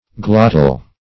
Glottal \Glot"tal\, a.